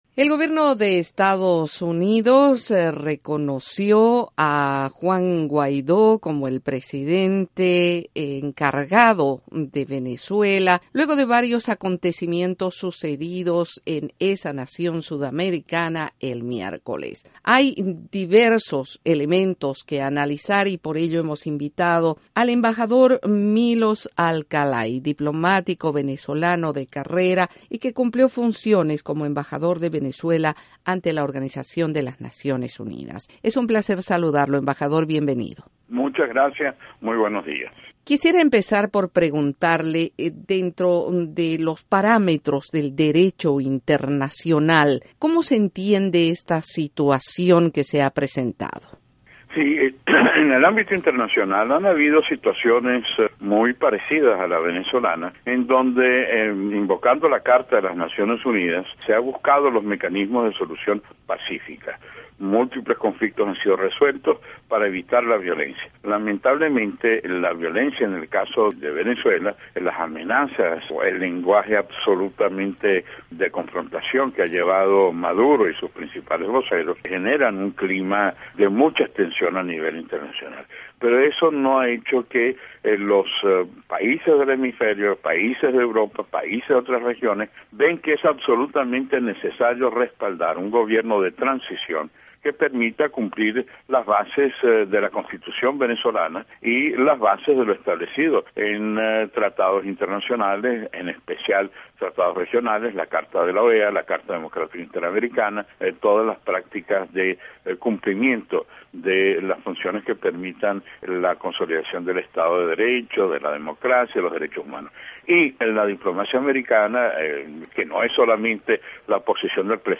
La Voz de América entrevistó al diplomático venezolano, Milos Alcalay, ex embajador de Venezuela ante la Organización de las Naciones Unidas y analista de política exterior.
En esta entrevista, el ex embajador venezolano ante la ONU también se refiere a la importancia de las decisiones estadounidenses que son, en gran medida, un referente para otras naciones.